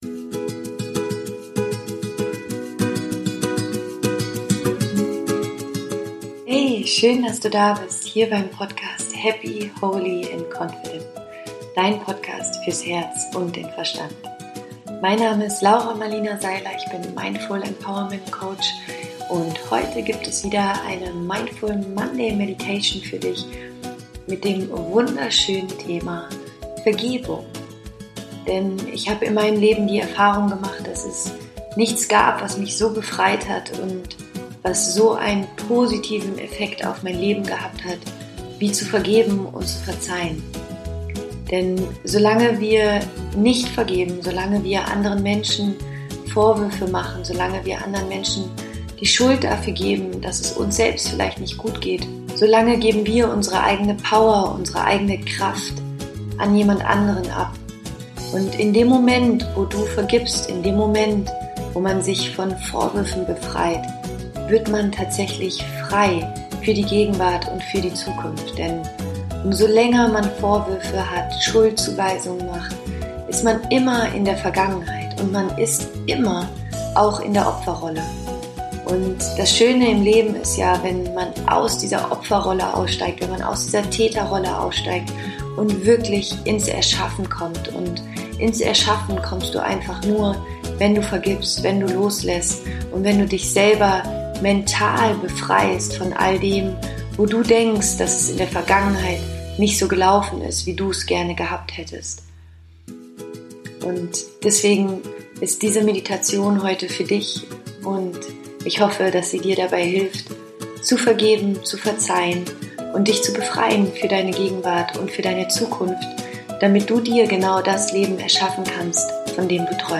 Zu Vergeben ermöglicht dir, deine Vergangenheit abzuschließen und frei in die Zukunft zu blicken. Die Meditation ist eine Anleitung zu Vergebung.